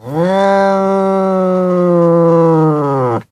sounds / monsters / cat / c2_threat_2.ogg
c2_threat_2.ogg